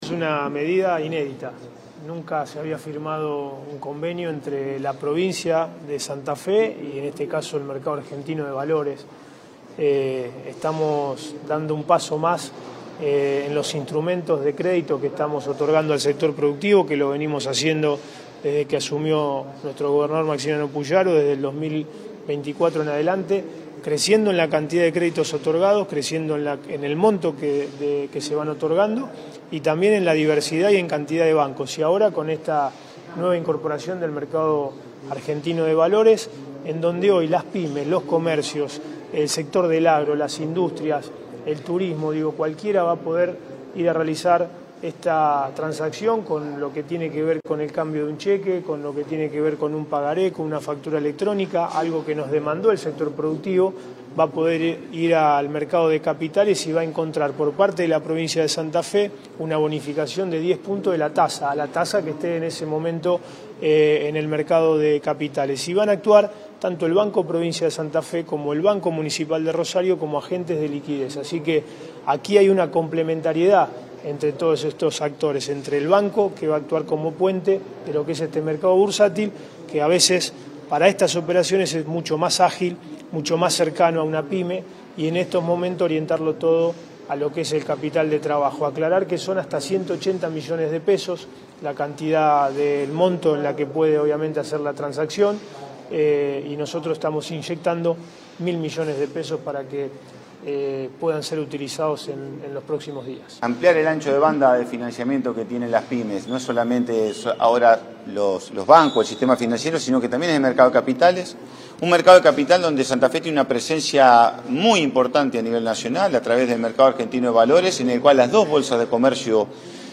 El acto se desarrolló en la sede del la Bolsa de Comercio de Santa Fe, donde se brindaron detalles del Convenio de Bonificación de Tasas que permitirá a empresa acceder a financiamiento productivo más competitivo a través del mercado de capitales.